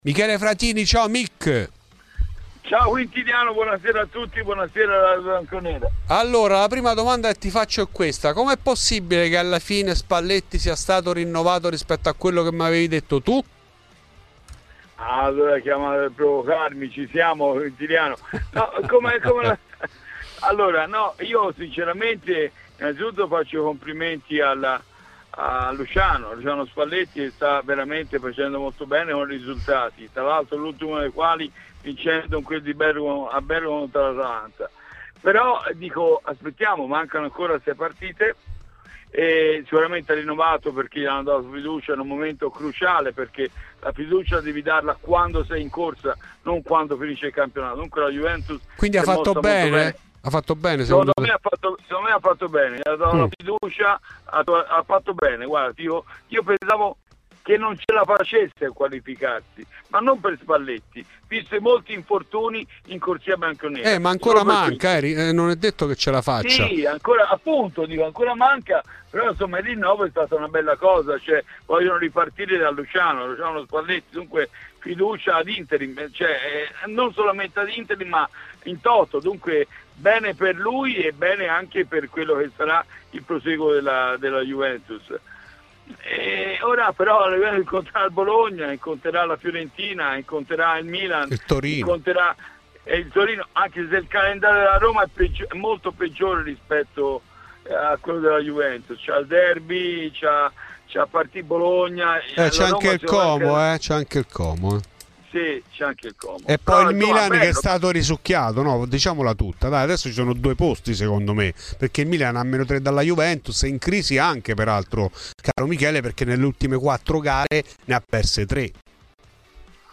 talent scout e intermediario di mercato